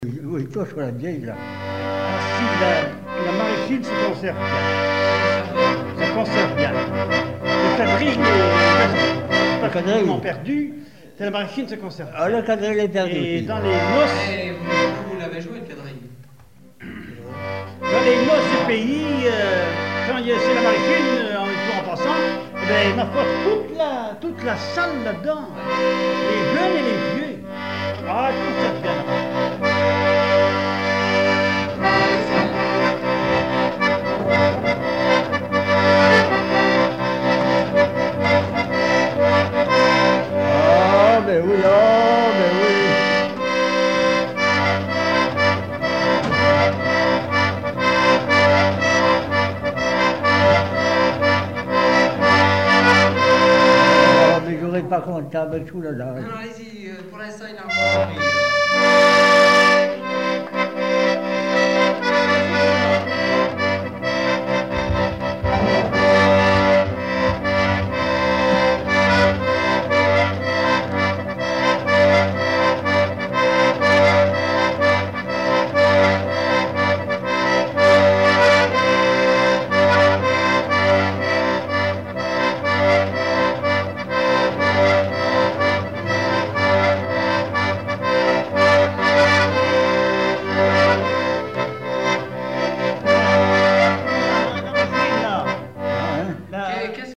danse : branle : courante, maraîchine
chansons populaires et instrumentaux
Pièce musicale inédite